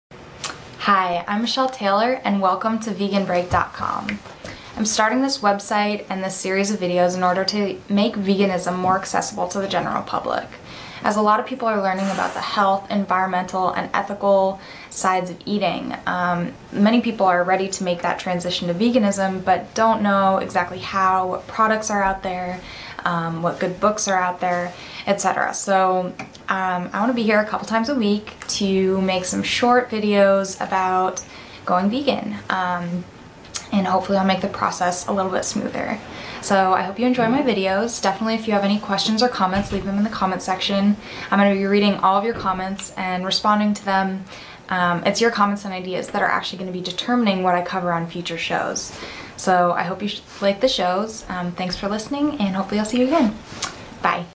I thought it was fine - though I think you can hear the camera person breathing!
Hahha that actually isn't the camera person...it's my dog.
Her delivery is all over the place, volume wise (IMHO). Here's the audio with DRC.
That's louder (which is fine - by all means peak normalise it), but with subtle DRC pumping added all over it.
Sounds like you are using the camcorder mic probably with AGC on. That causes the uneven echo sound.
dynamic_range_compression.mp3